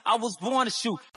i was born a shoe Meme Sound Effect